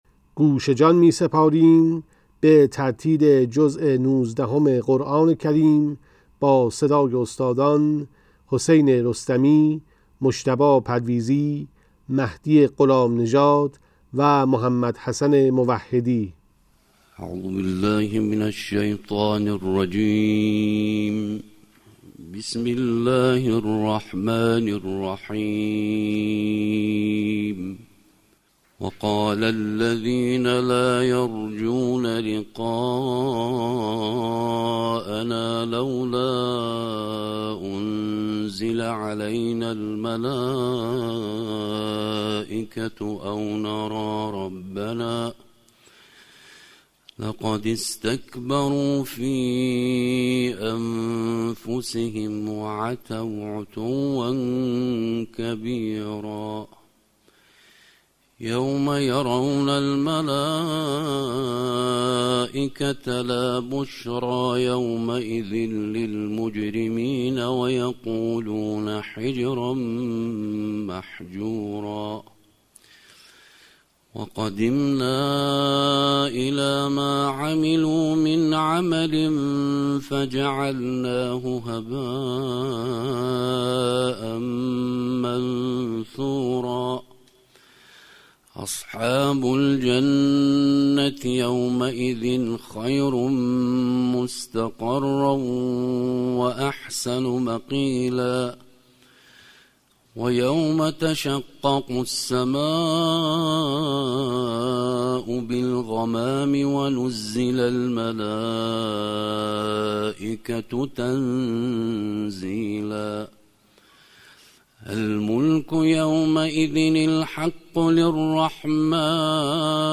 قرائت ترتیل جزء نوزدهم قرآن با صدای قاریان بین‌المللی + صوت
نسخه باکیفیت تلاوت جزء نوزدهم قرآن با صدای قاریان بین‌المللی
این تلاوت‌ها طی روزهای ماه مبارک رمضان سال گذشته (۱۴۴۵ ه‌ق) از سوی بخش فرهنگی بیمارستان خاتم‌الانبیاء(ص) تهران در نمازخانه بیمارستان اجرا و ضبط شده و برای نخستین‌بار منتشر می‌شود.